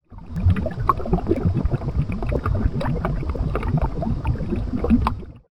lava.ogg